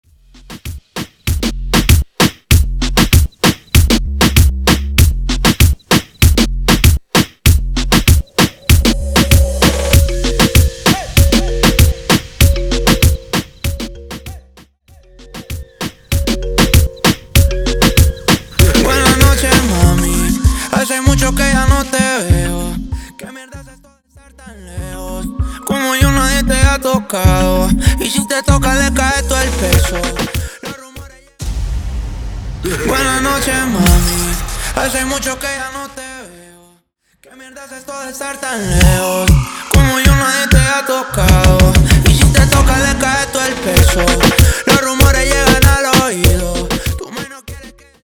Intro Dirty, Open Show Dirty